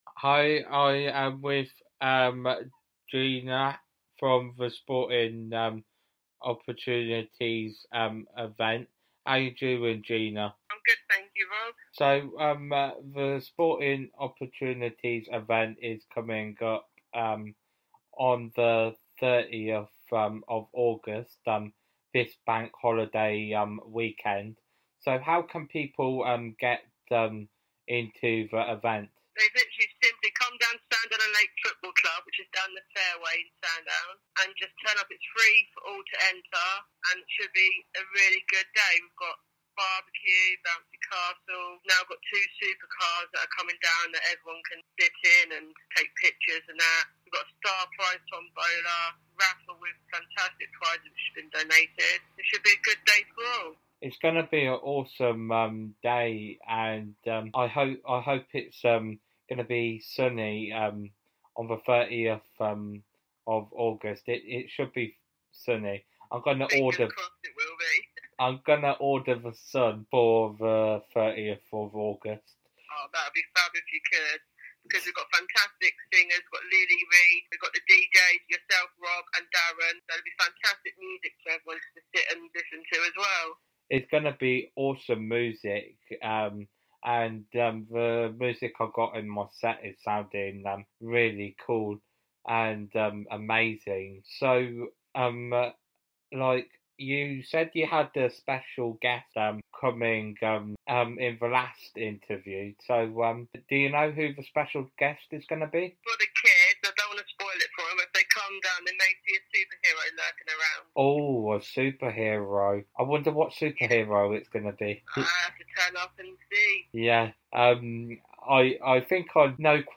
Sporting Opportunities Event/Festival Second Interview